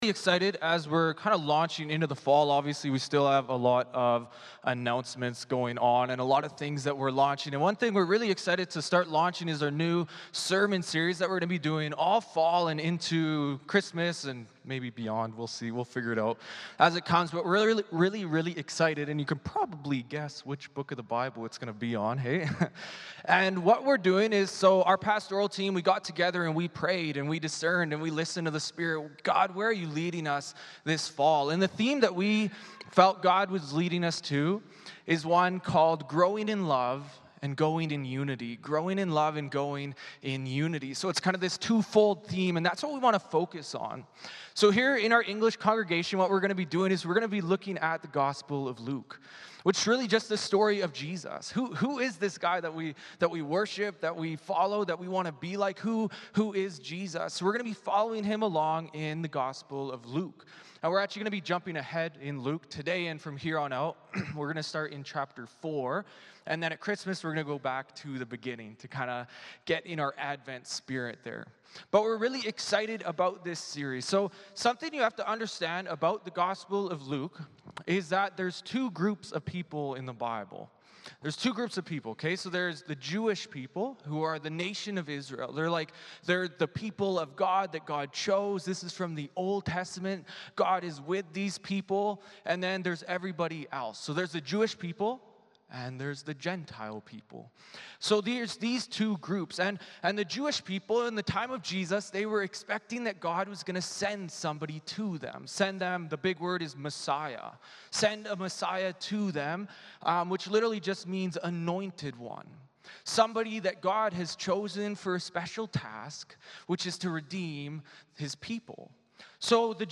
Passage: Luke 4:14-44 Service Type: Sunday Morning Service Passage